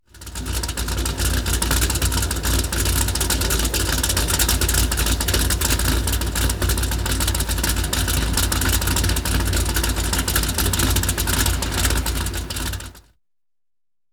Sports Car Idle Rough Sound
transport
Sports Car Idle Rough